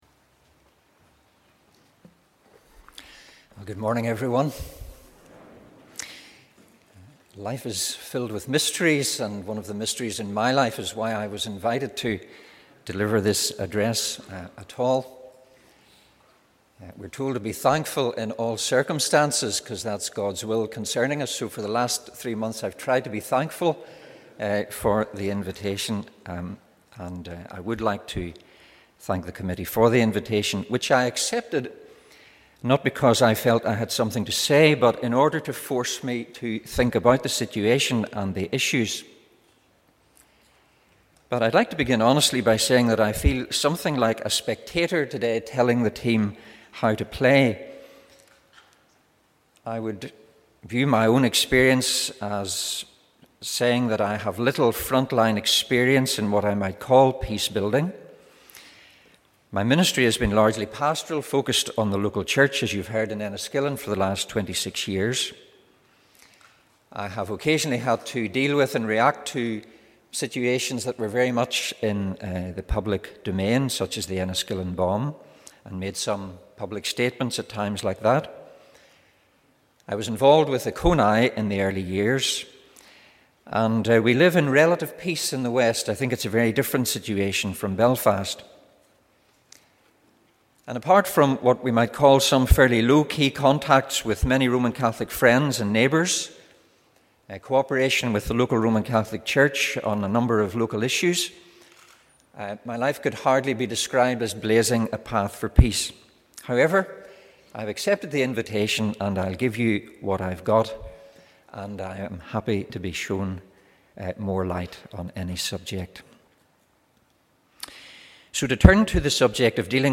On Saturday, 5th April Presbyterians gathered in Assembly Buildings for a morning conference entitled 'Dealing with the Past, Shaping the Future'.